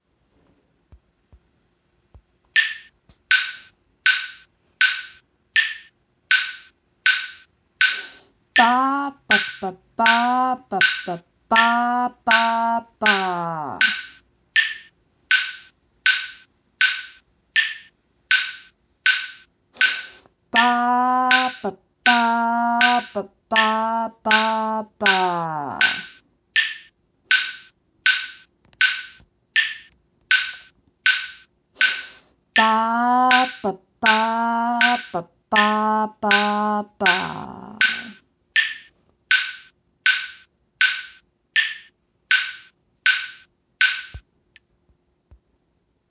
Move to the beat as you listen and echo.
What this looks like Notice the last two rhythms sound the same, even though they look different.
Duple-dotted-additional-in-4-4.mp3